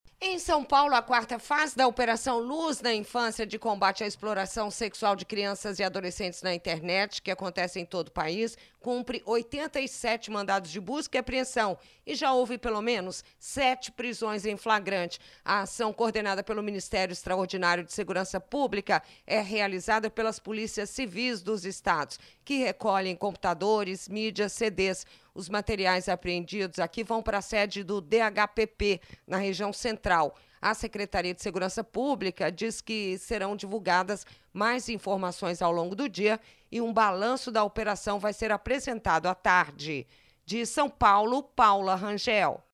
De São Paulo